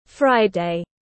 Thứ 6 tiếng anh gọi là friday, phiên âm tiếng anh đọc là /ˈfraɪ.deɪ/
Friday /ˈfraɪ.deɪ/